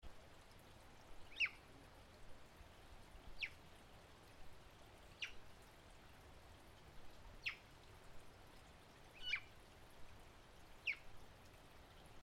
斯文豪氏赤蛙 Odorrana swinhoana
台北市 士林區 陽明山坪頂古圳
錄音環境 溪澗
1隻鳴叫